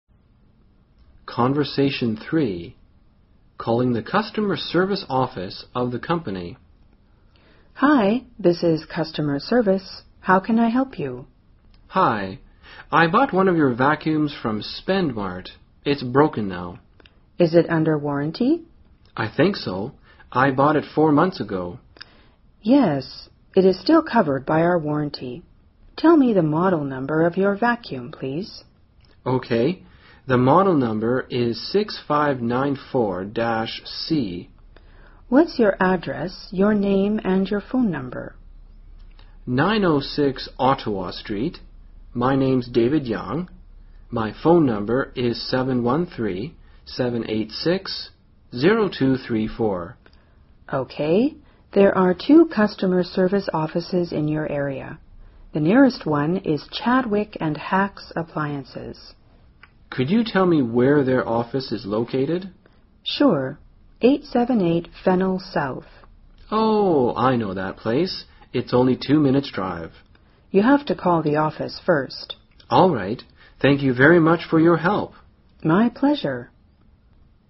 【对话3：打电话给公司的客服部】